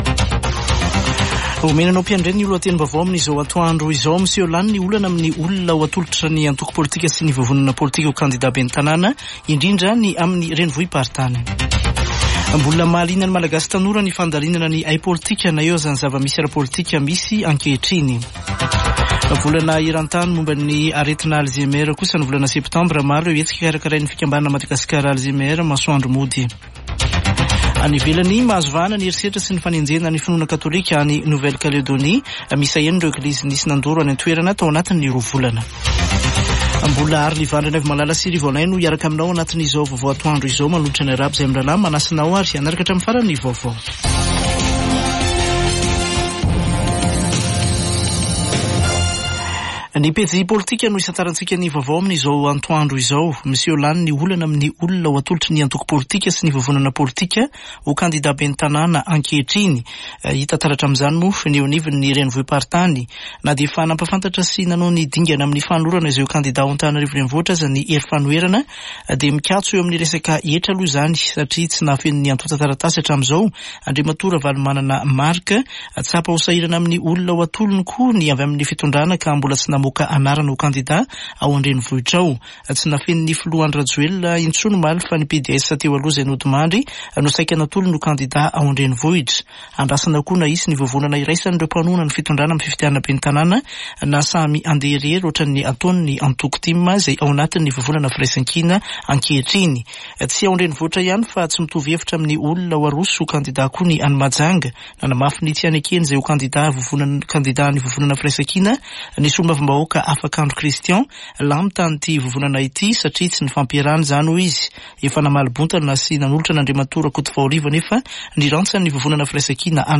[Vaovao antoandro] Talata 17 septambra 2024